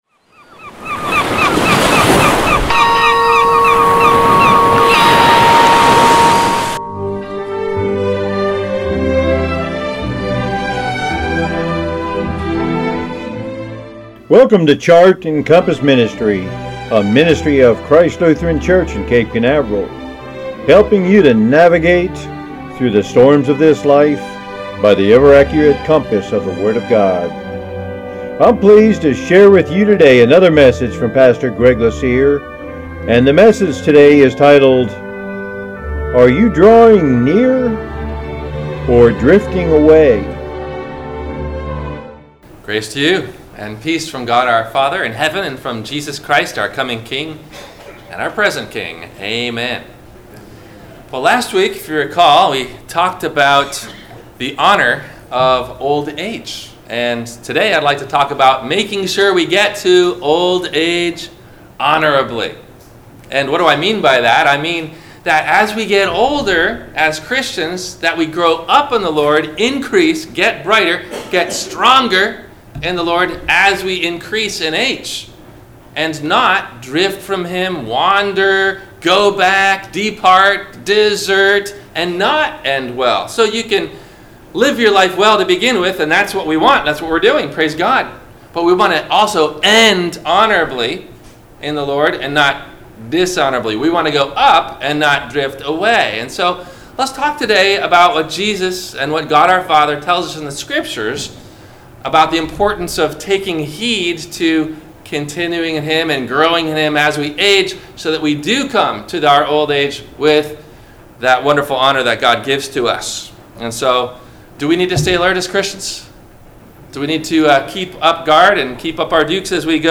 – WMIE Radio Sermon – February 11 2019 - Christ Lutheran Cape Canaveral